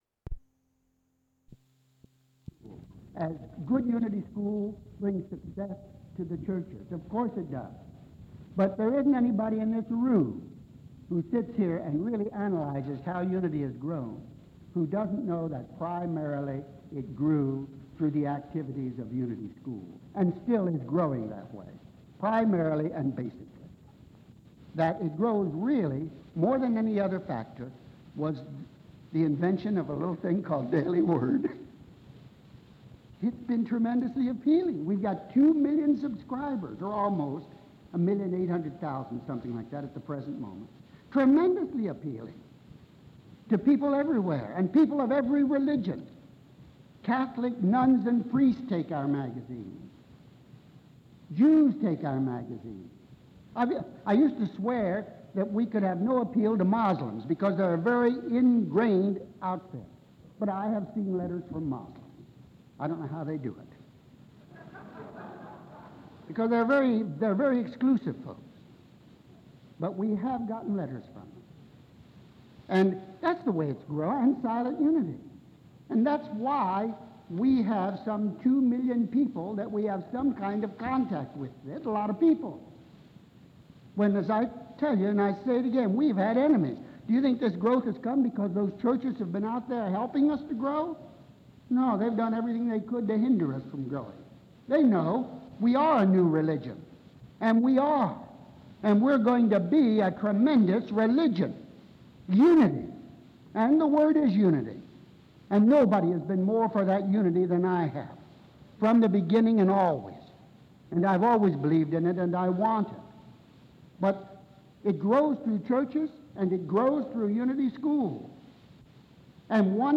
Unfortunately, the audio file is incomplete.